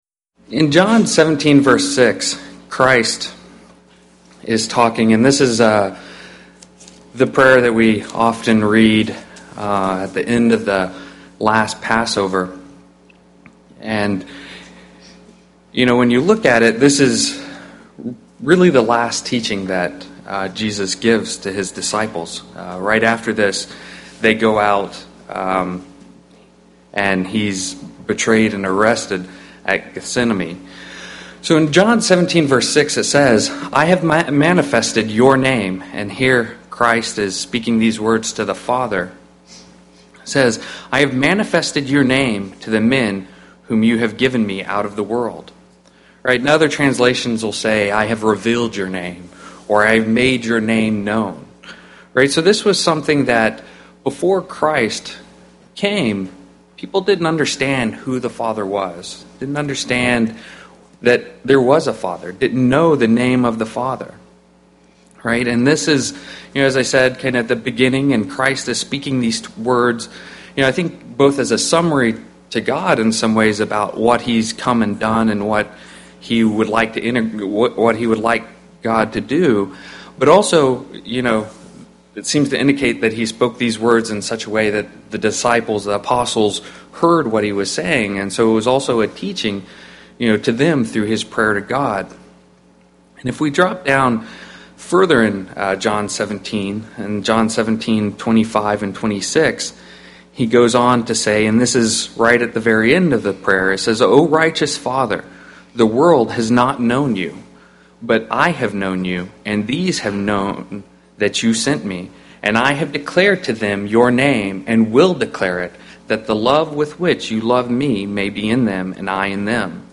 Given in Seattle, WA
UCG Sermon doctrine Studying the bible?